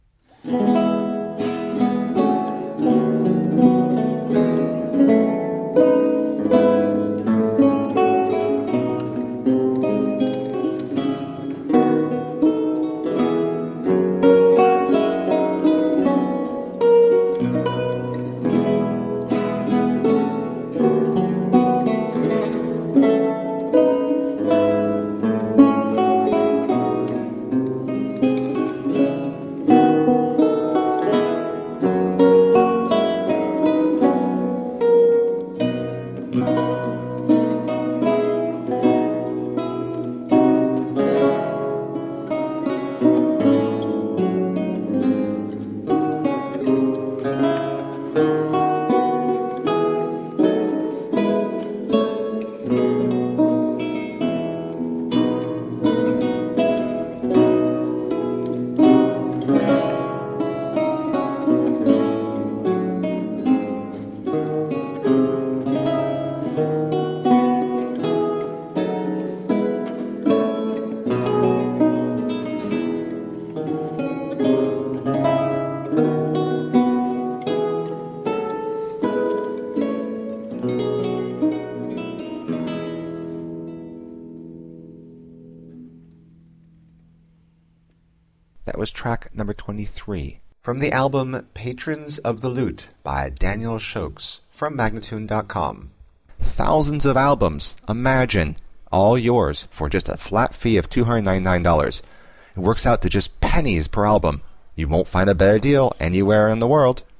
A feast of baroque lute.
Classical, Baroque, Instrumental